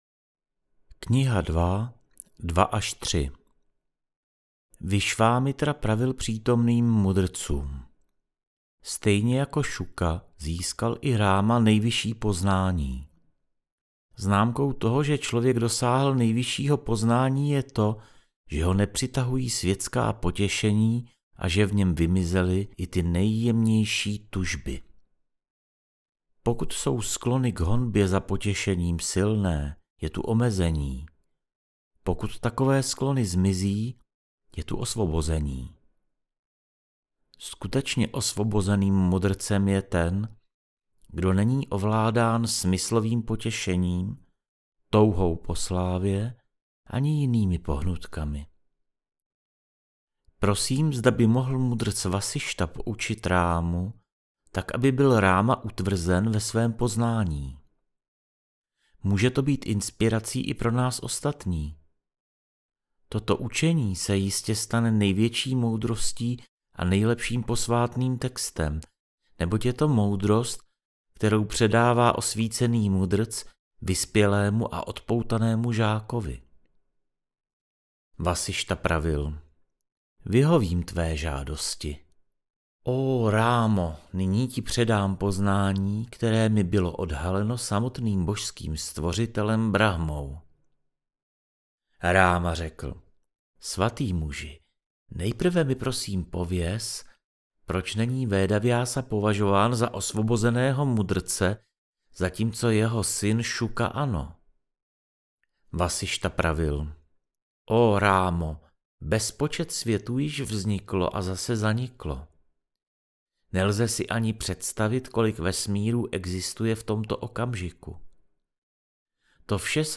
JÓGA VÁSIŠTHA - AUDIOKNIHA